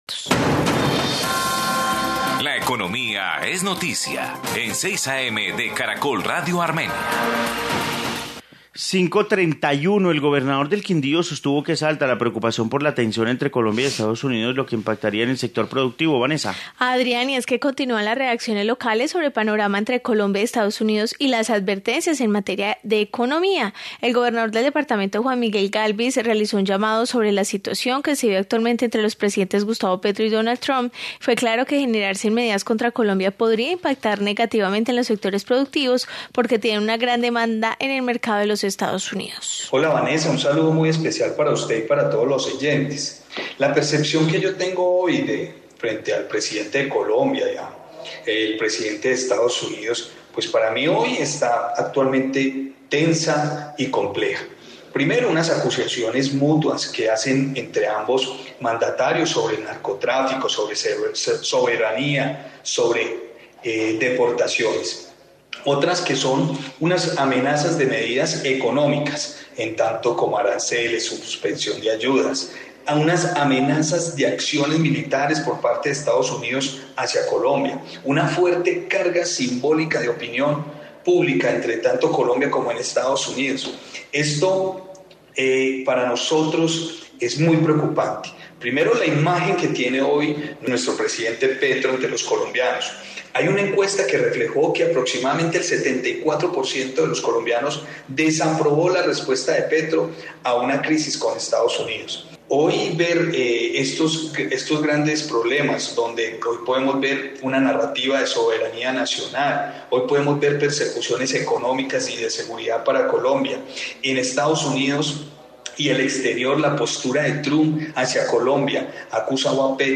Informe sobre situación de Colombia y EE.UU